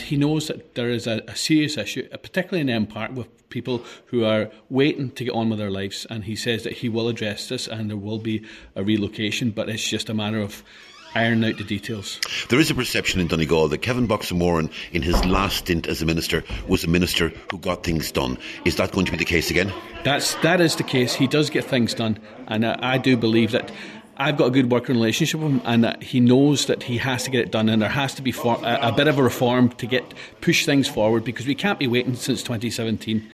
100% Redress Deputy Charles Ward says he’s had several discussions with the minister, and believes he is committed to resolving the issue……..